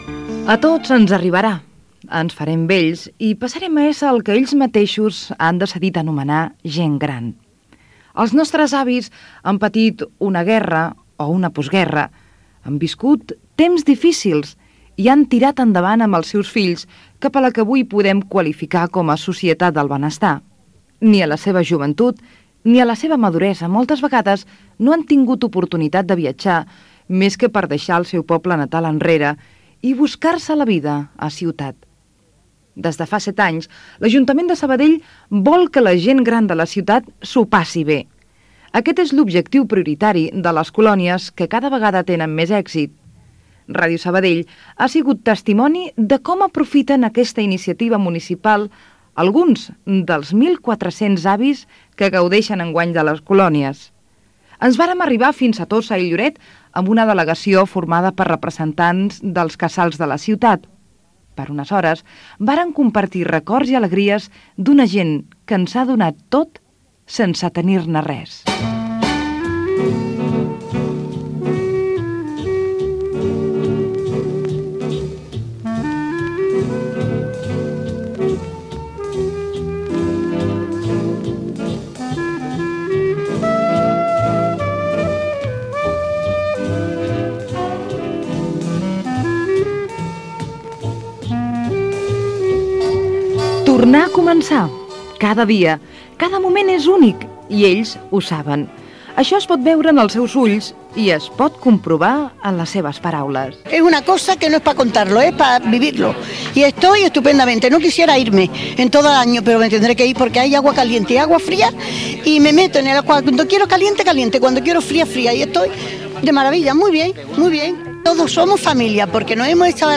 Reportatge sobre les colònies per la gent major organitzades per l'Ajuntament de Sabadell